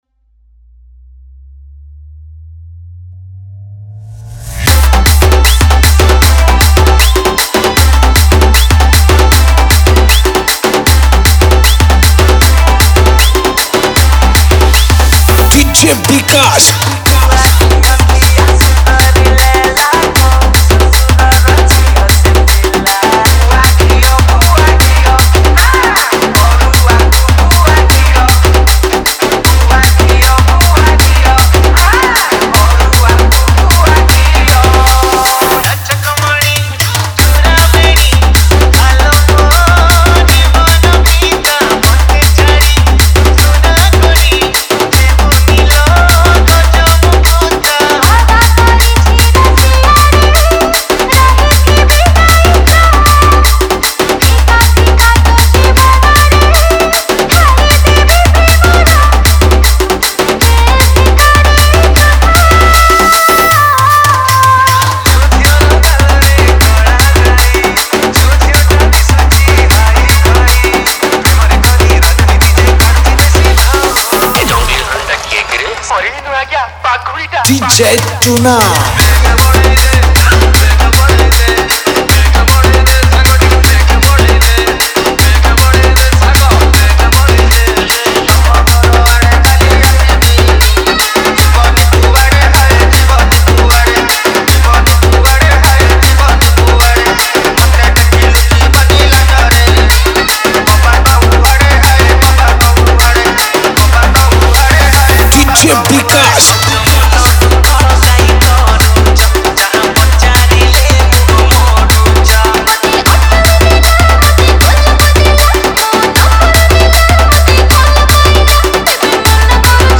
Ganesh Puja Special Dj 2023